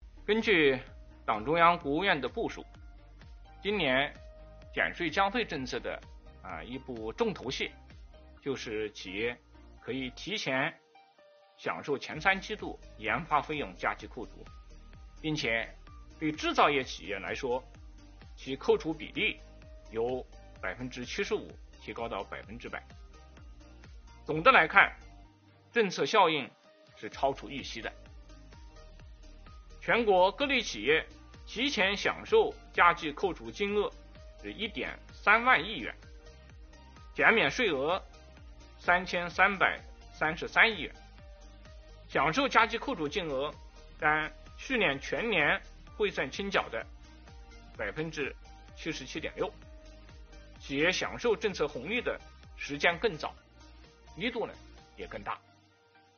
11月5日，国务院新闻办公室举行国务院政策例行吹风会，国家税务总局副局长王道树介绍制造业中小微企业缓税政策等有关情况，并答记者问。